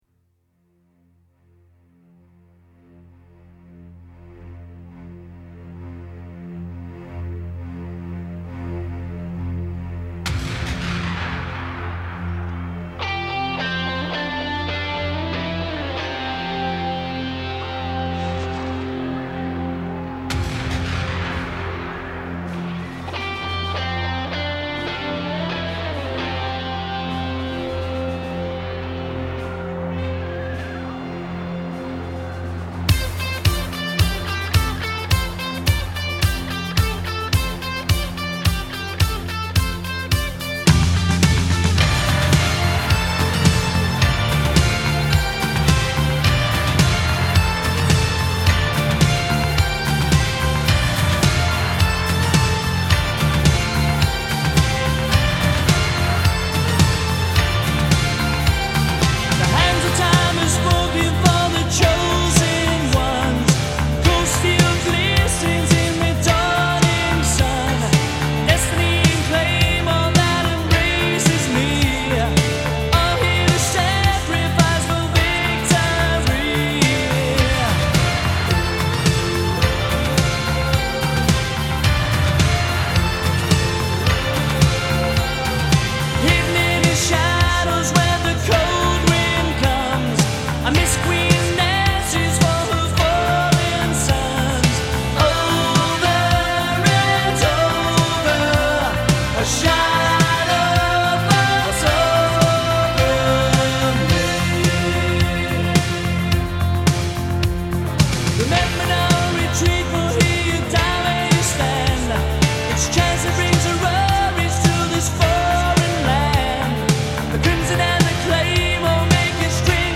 The whole album is a solid slice of mid-’80s pop rock